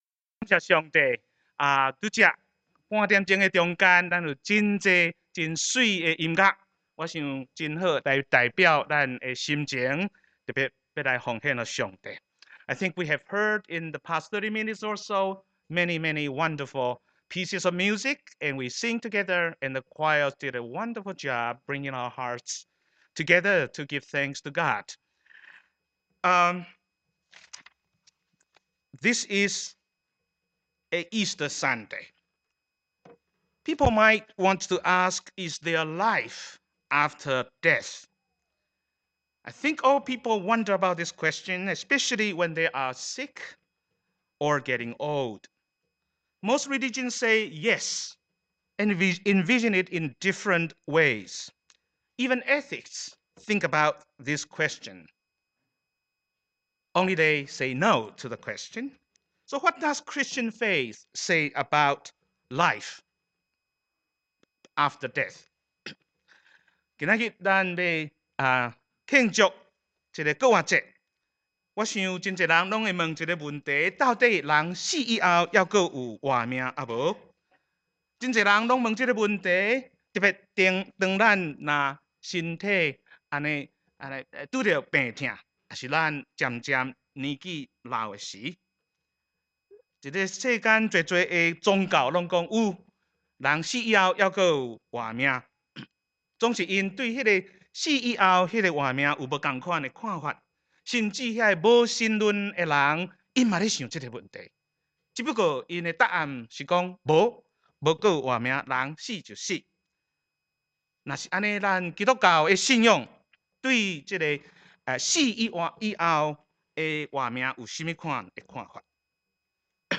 We are Blessed Preacher